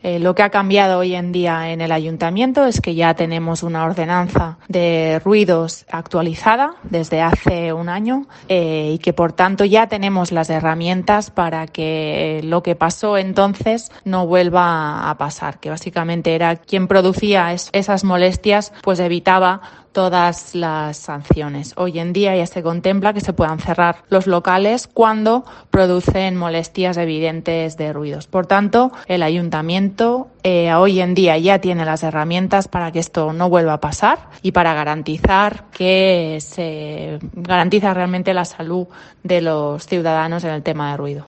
Mariana Boadella, portavoz del equipo de gobierno de Ciudad Real